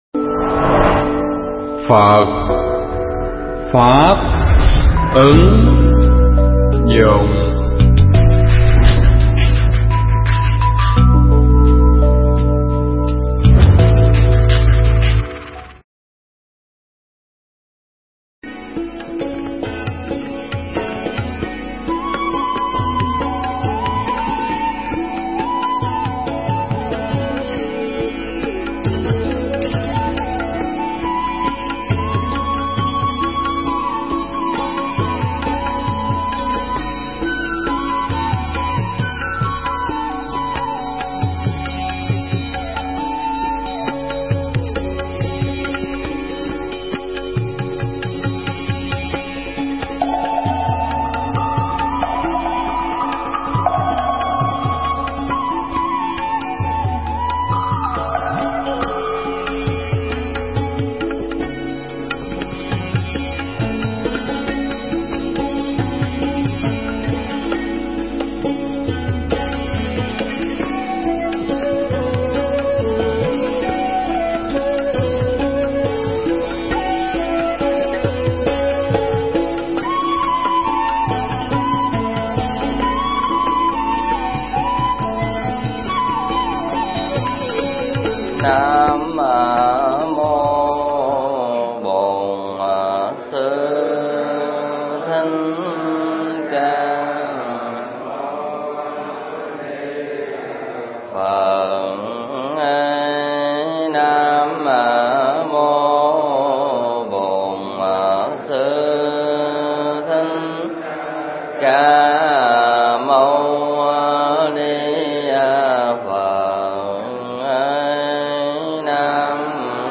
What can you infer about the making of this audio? thuyết giảng tại Tu Viện Tường Vân